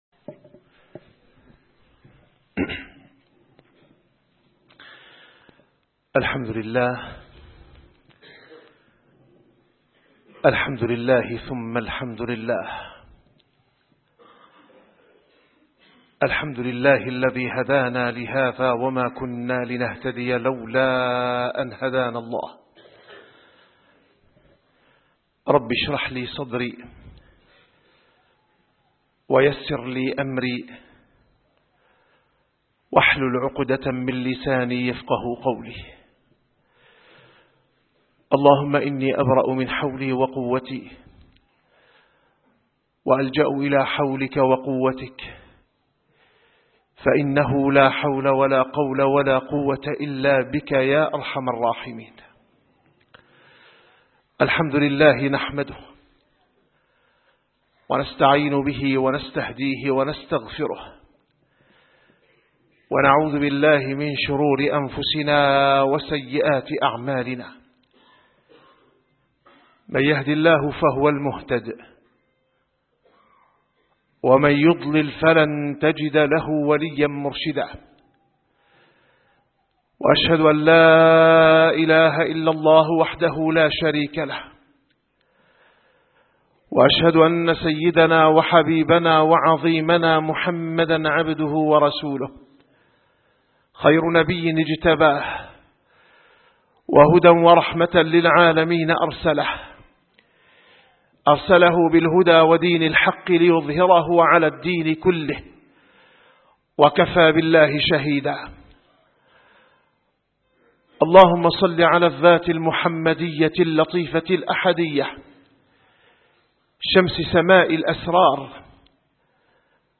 - الخطب - من مظاهر المعجزات الإلهية في مسيرة الدعوة النبوية